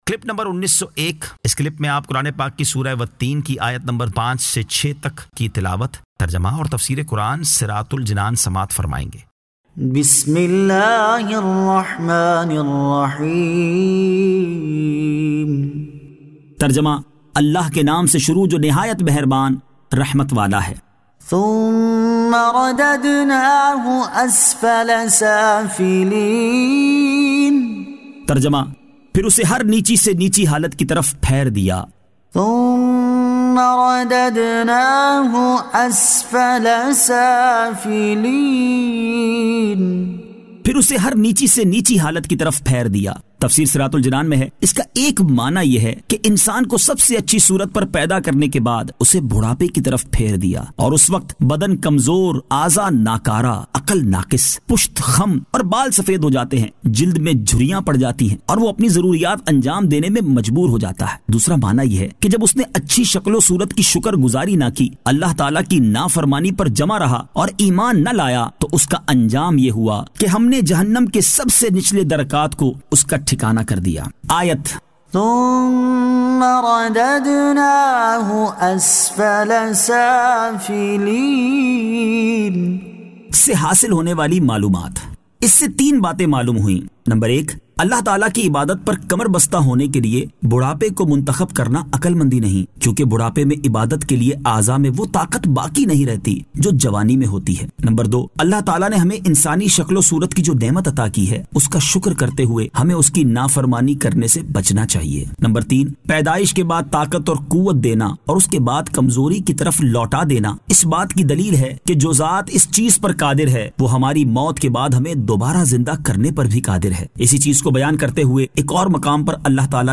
Surah At-Teen 05 To 06 Tilawat , Tarjama , Tafseer